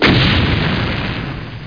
1 channel
BARRELEX.mp3